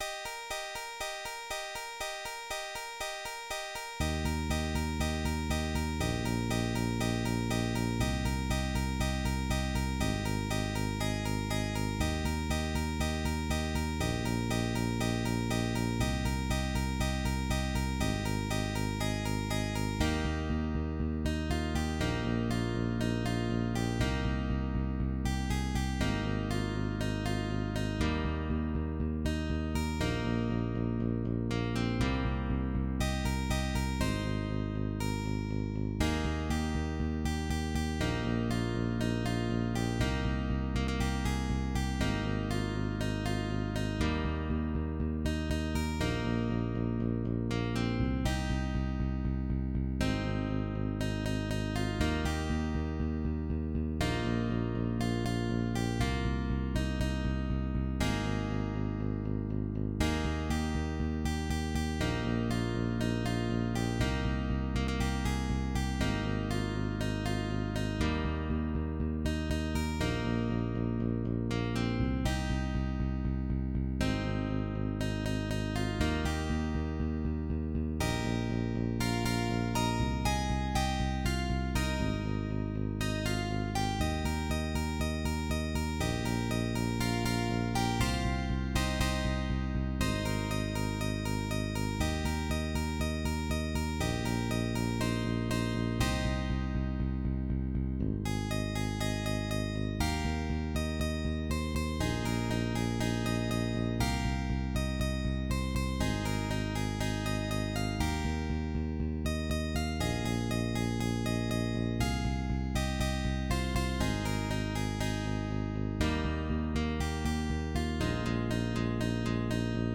MIDI Music File
2 channels